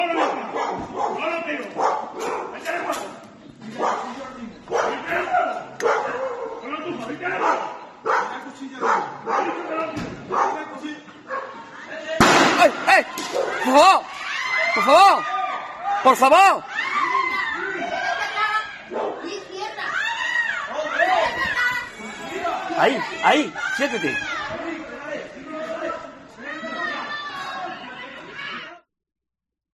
Momento en el que se dispara el arma del agente
Jaén - Huelma